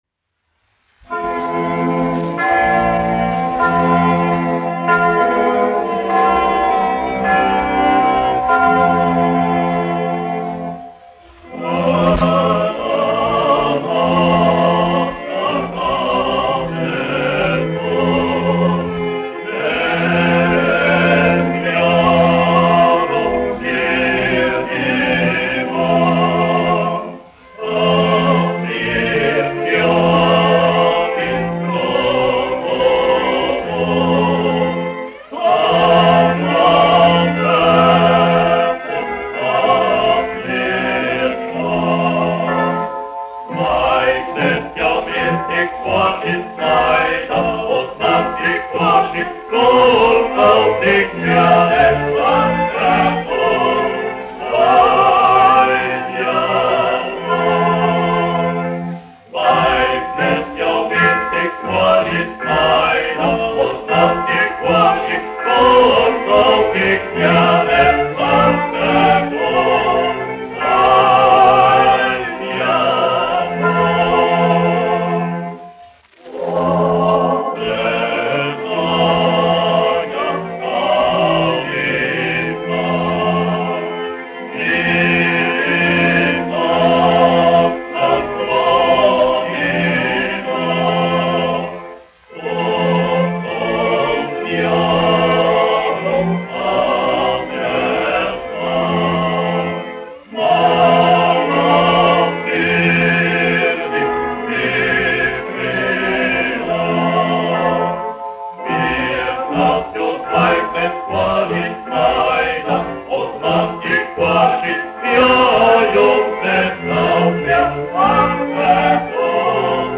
1 skpl. : analogs, 78 apgr/min, mono ; 25 cm
Ziemassvētku mūzika
Kori (vīru) ar orķestri
Skaņuplate
Latvijas vēsturiskie šellaka skaņuplašu ieraksti (Kolekcija)